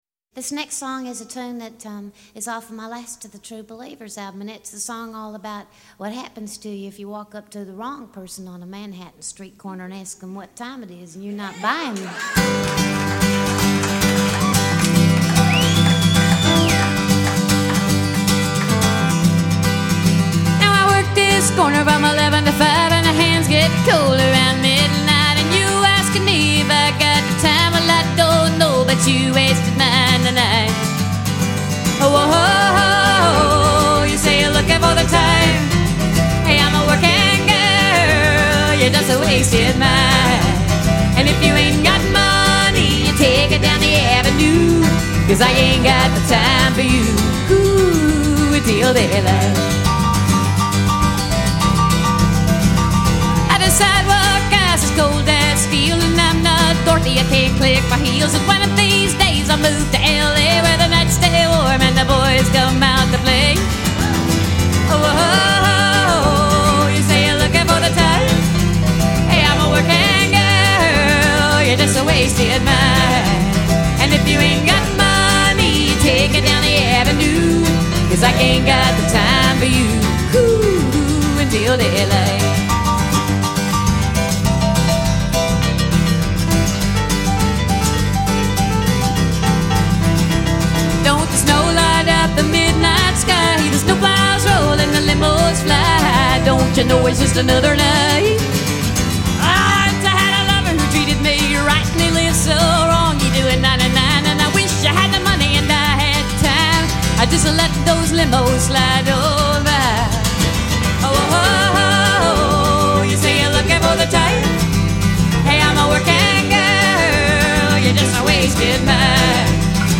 Sweet-voiced songstress